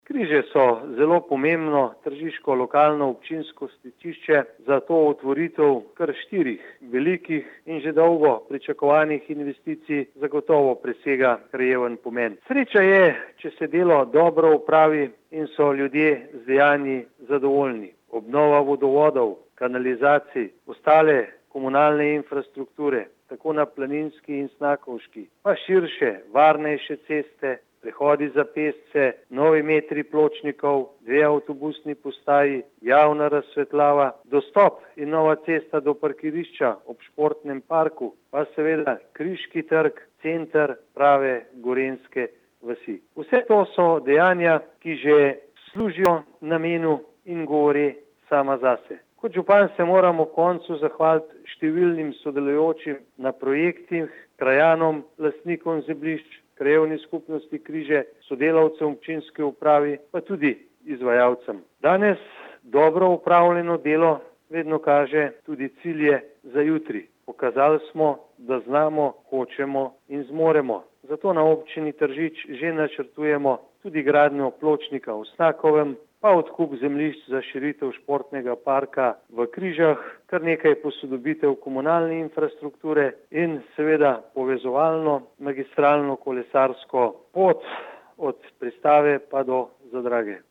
97147_mag.borutsajoviczupanobcinetrziczamojoobcino.mp3